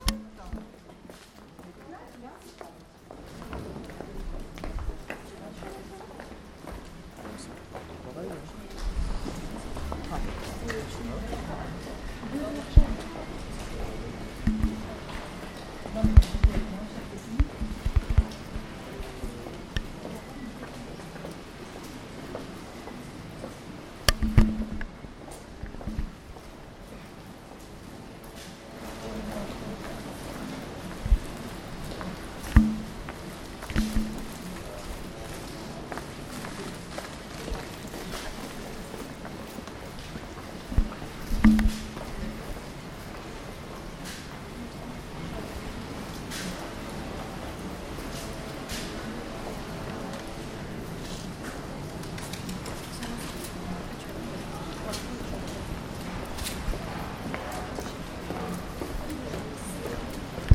Limoges: Rue du Clochet
Passant